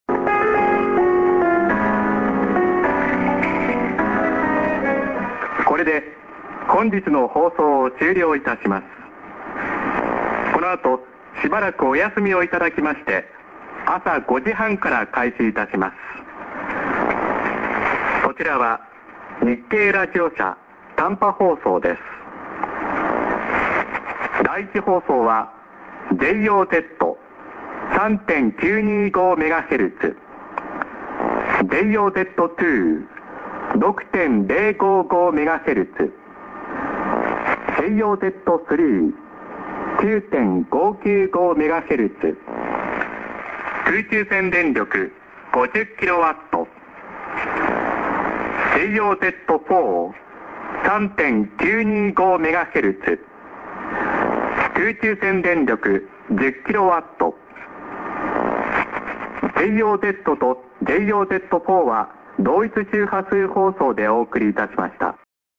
prog->59':ANN(man:ID+SKJ)->s/off